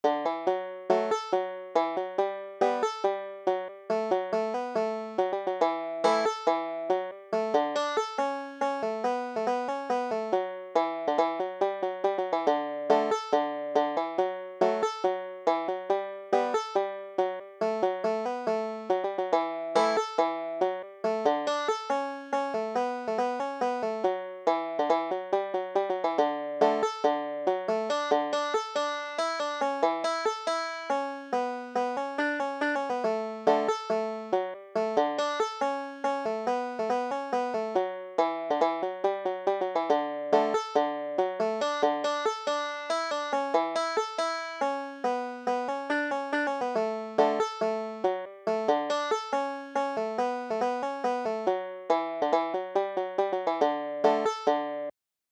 Banjo
Coleman's March Double C D   tab | audio tab |